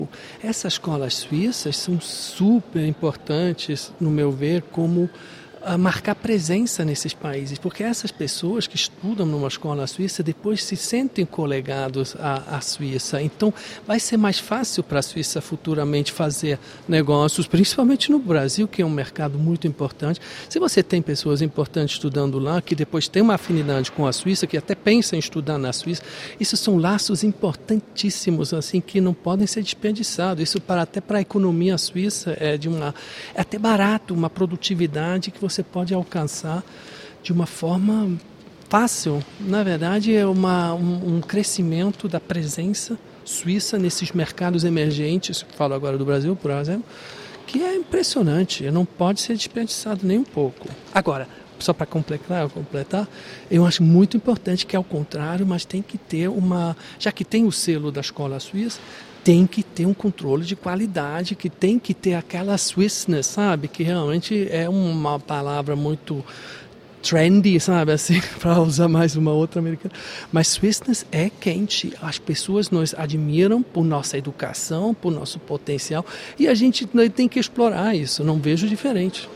suíço-brasileiro radicado no Rio de Janeiro.